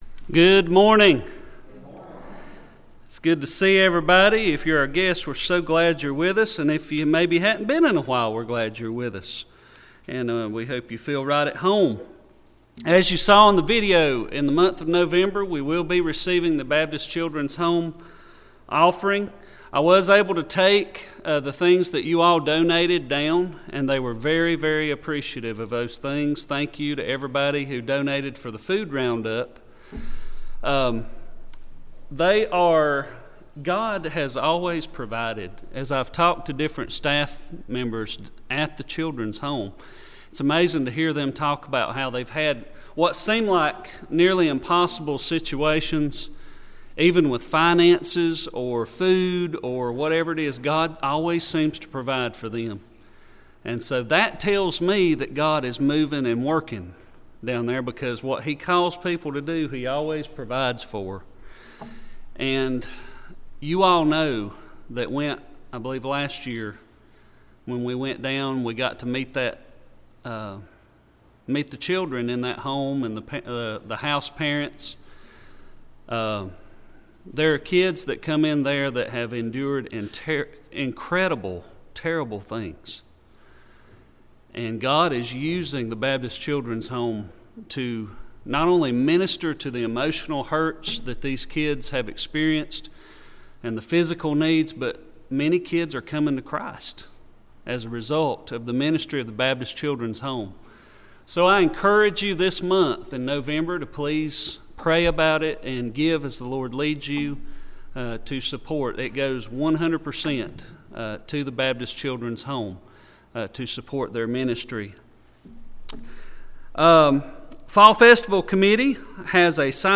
(Click Logo to Return to Home Page) Sunday Worship Stream November 1, 2020 10:00am Worship Service CLICK HERE to Listen (Audio Only) Worship Song: "Start a Fire" Stream unavailable due to copyright restrictions.